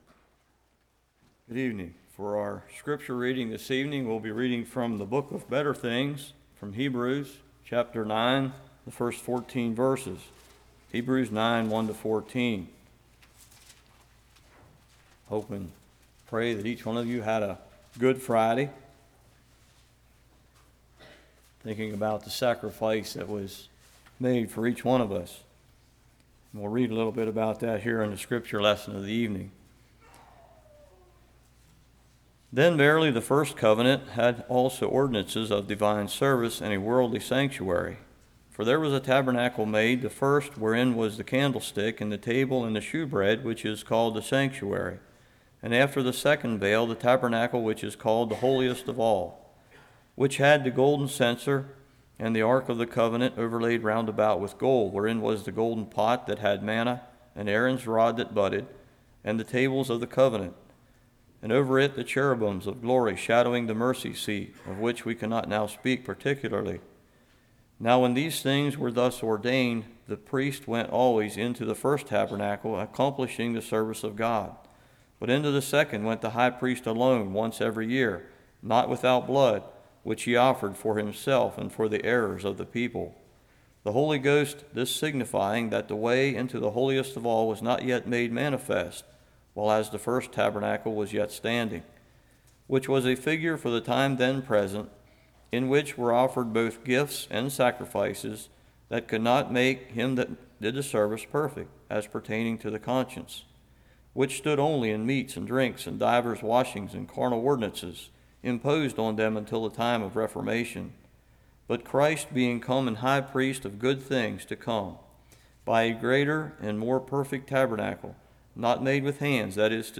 Hebrews 9:1-14 Service Type: Good Friday Jesus’ Blood Day of Atonement Cleansed conscience « Carved in Stone Temperance